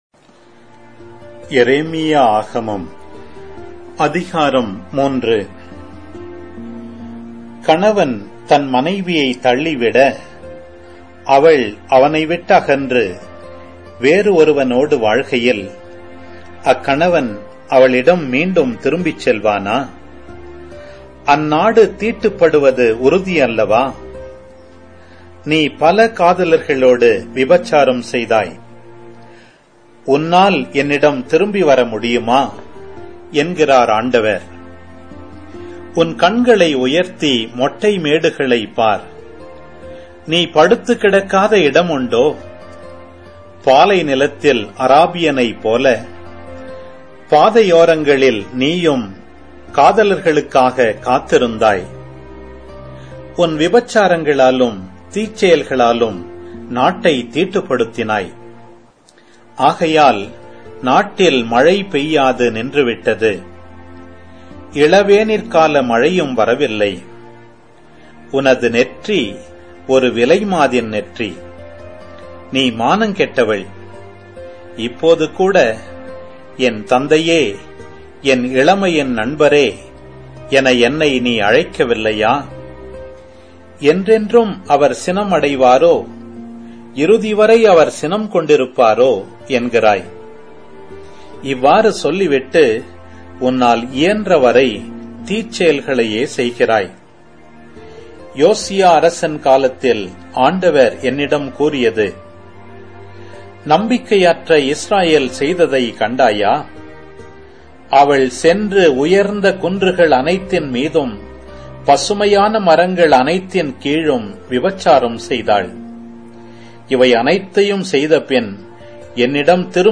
Audio Bible